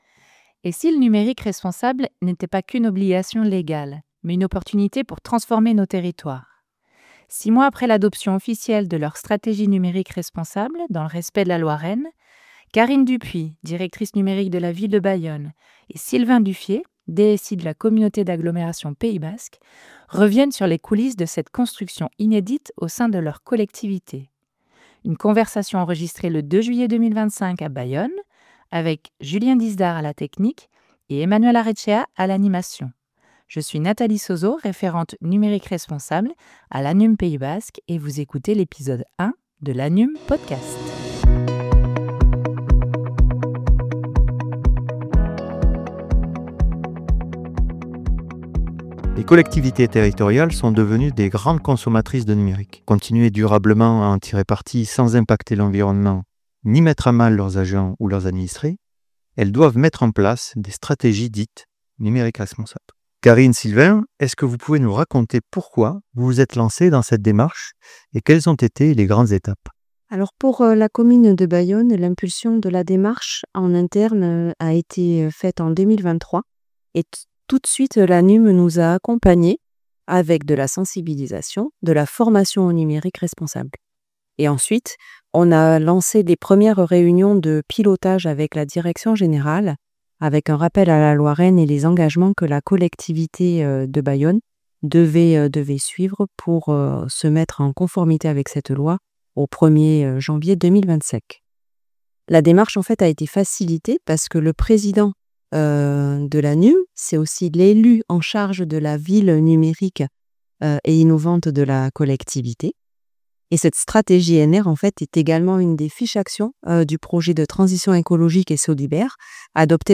LaNumPodcast#1 - ITW croisée Bayonne&CAPB Stratégie NR Loi REEN